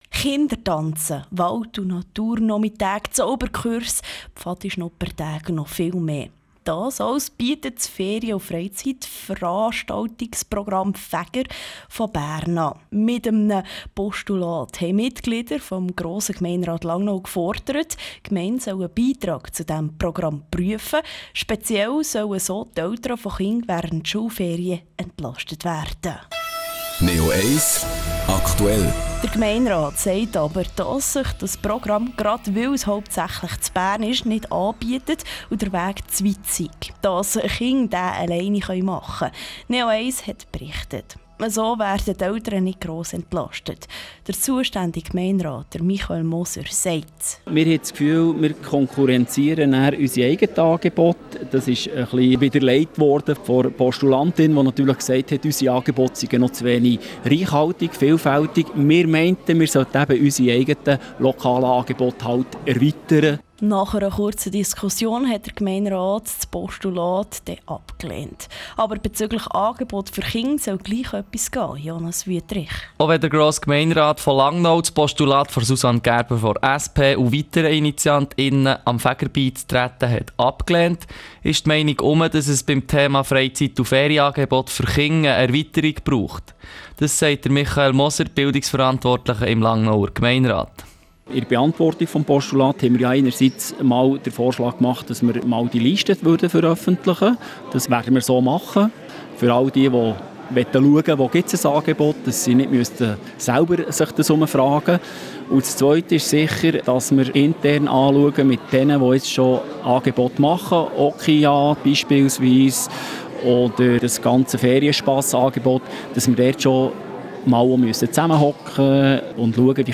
Geld, das Langnau verwenden kann, sagt der zuständige Gemeinderat Johann Sommer im Gespräch mit neo1.
Nach anfänglichen Startschwierigkeiten sei am OSLA Normalität eingekehrt, sagt er gegenüber neo1.